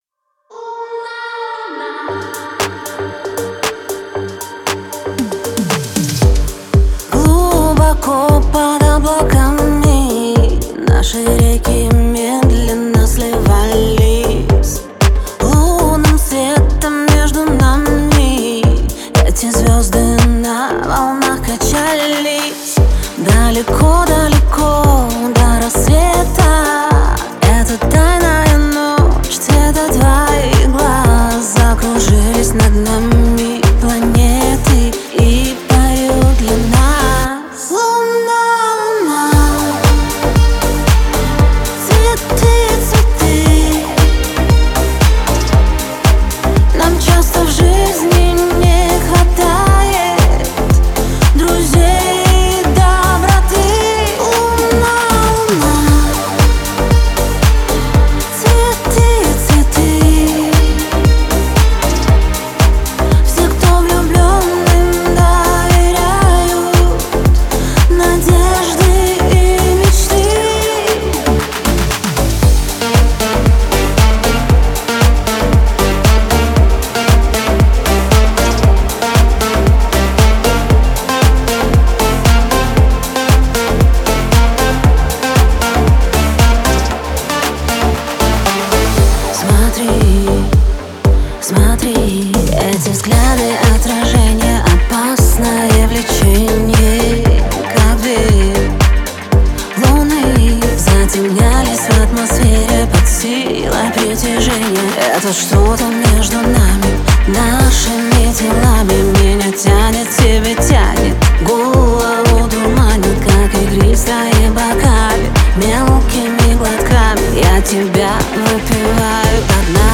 ремейки песен , каверы